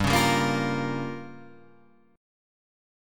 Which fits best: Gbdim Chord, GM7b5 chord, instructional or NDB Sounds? GM7b5 chord